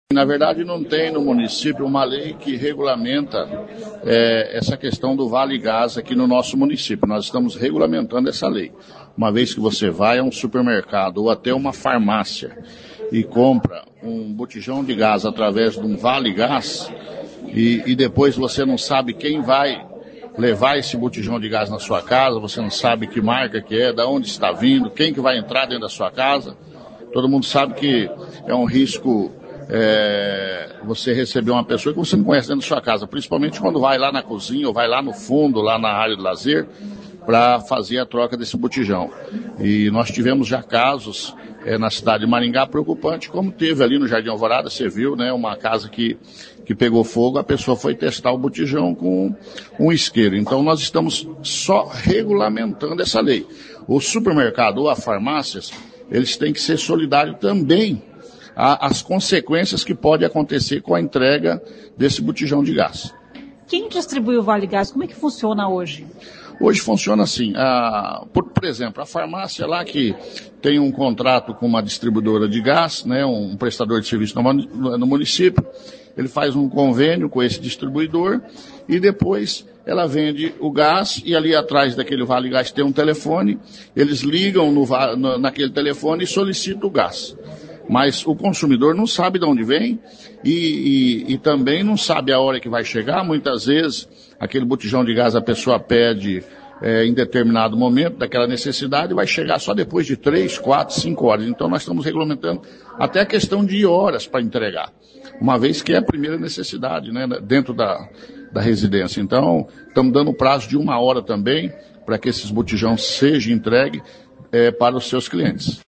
Ouça o que diz o vereador sobre a proposta de lei, que ainda está tramitando na Câmara Municipal de Maringá.